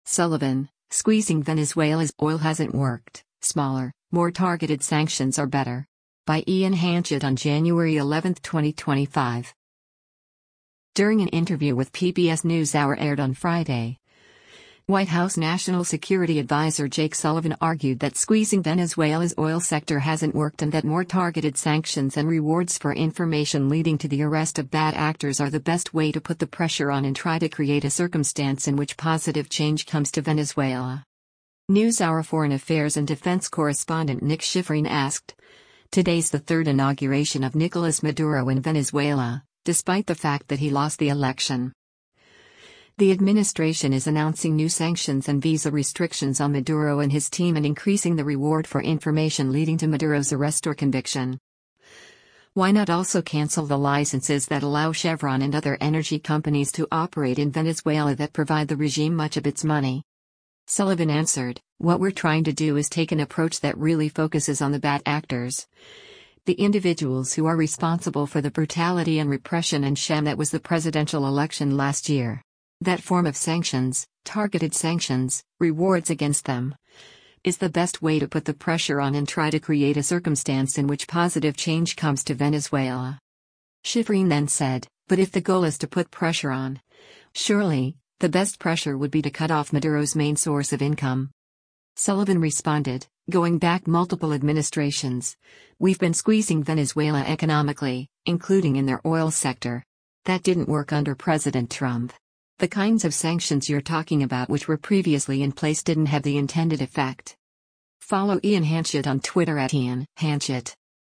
During an interview with “PBS NewsHour” aired on Friday, White House National Security Adviser Jake Sullivan argued that squeezing Venezuela’s oil sector hasn’t worked and that more targeted sanctions and rewards for information leading to the arrest of bad actors are “the best way to put the pressure on and try to create a circumstance in which positive change comes to Venezuela.”